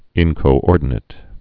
(ĭnkō-ôrdn-ĭt, -āt)